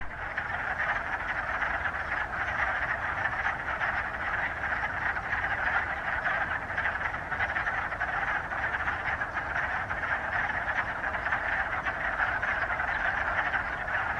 woodfrogs.m4a